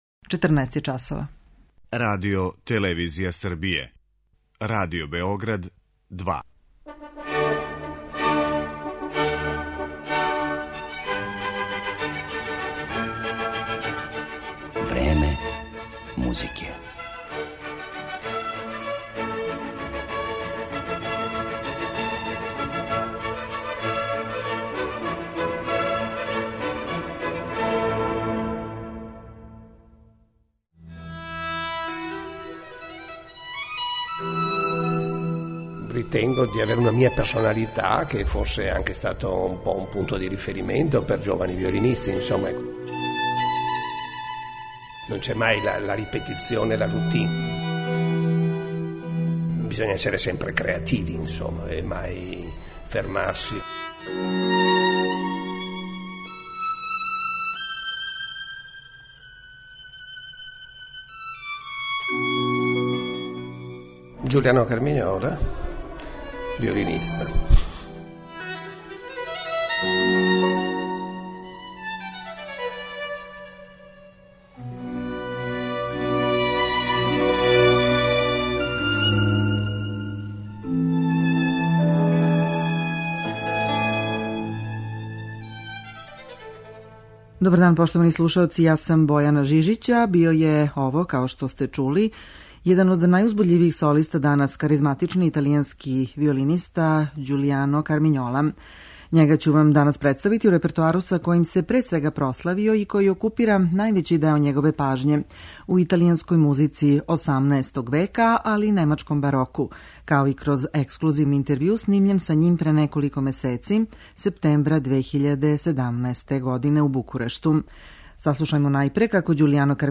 Italijanski violinista Đulijano Karminjola.
Kao jedinstvenog, uzbudljivog i harizmatičnog, opisuju kritičari italijanskog violinistu Đulijana Karminjolu, kome je posvećena današnja emisija. Ovog izuzetnog i neobičnog umetnika predstavićemo u repertoaru sa kojim se pre svega proslavio i koji okupira najveći deo njegove pažnje ‒ u italijanskoj muzici 18. veka, kao i kroz ekskluzivni intervju snimljen sa njim septembra 2017. godine u Bukureštu.